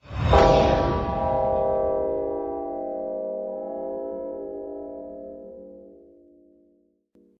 Bell2.ogg